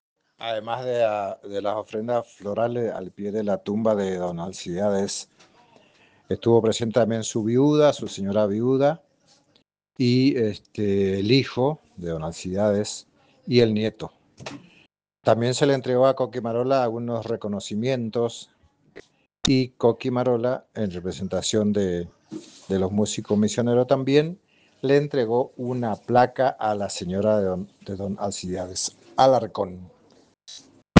En diálogo con la Redacción de Radio Tupambaé